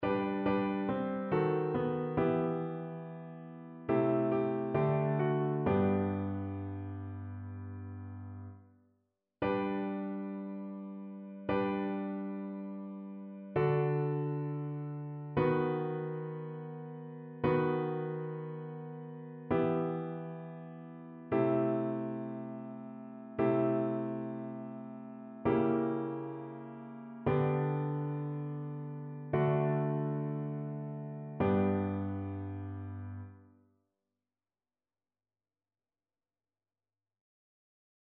ChœurSopranoAltoTénorBasse
annee-c-temps-ordinaire-30e-dimanche-psaume-33-satb.mp3